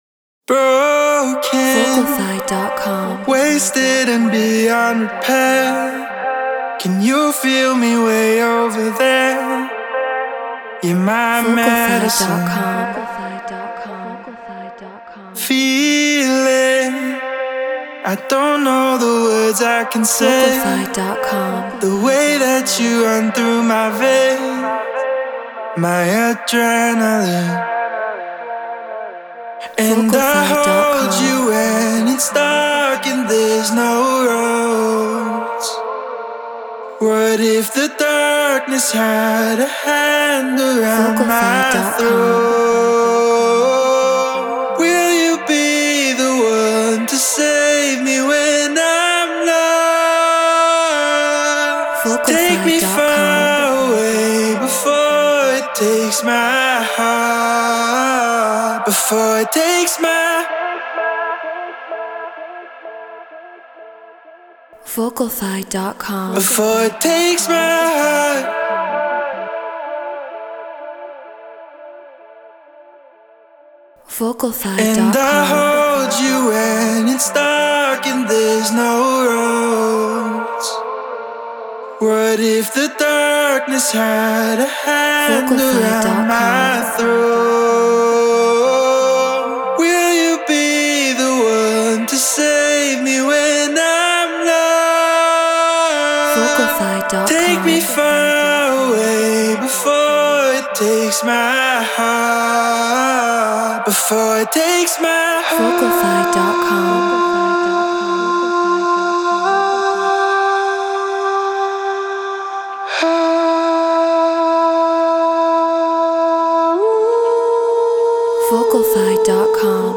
Melodic Techno 128 BPM Dmin